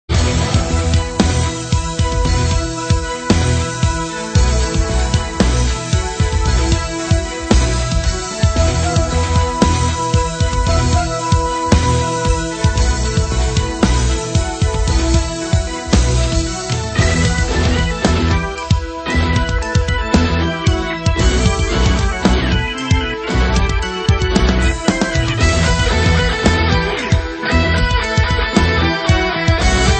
Your one-stop site for Commodore 64 SID chiptune remixes.